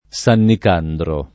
Sannik#ndro] top. (Puglia) — per esteso, Sannicandro Garganico (uffic. San Nicandro fino al 1862) e Sannicandro di Bari (uffic. San Nicandro fino al 1863) — sim. il cogn. Sannicandro